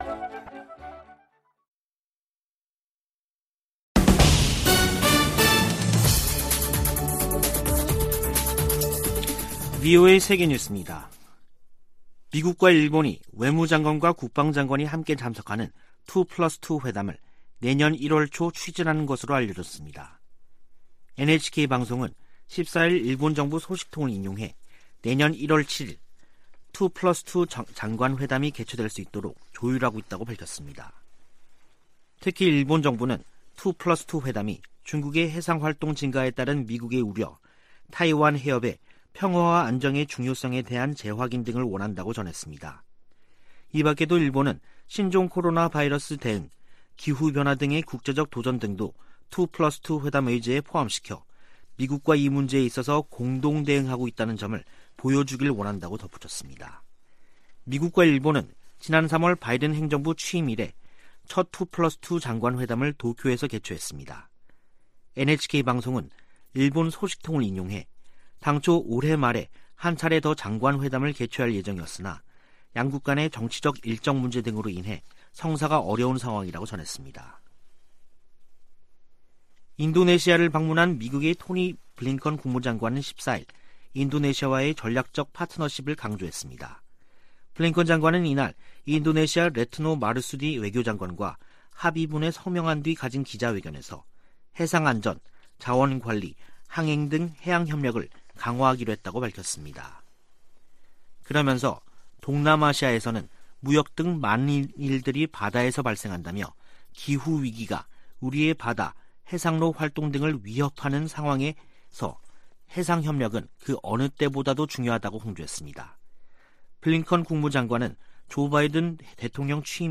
VOA 한국어 간판 뉴스 프로그램 '뉴스 투데이', 2021년 12월 15일 3부 방송입니다. 미국은 북한과 진지하고 지속적인 외교를 추구하고 있다고 토니 블링컨 국무장관이 밝혔습니다. 미 국무부는 북한의 해외 강제노역 관련 정보를 수집하고 있다면서 내용을 알고 있는 이들에게 정보를 제공해줄 것을 요청했습니다. 북한의 최근 탄도미사일 시험은 역내 미사일 방어 약화를 겨냥한 것으로 보인다고 미 의회조사국이 밝혔습니다.